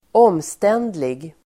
Uttal: [²'åm:sten:dlig]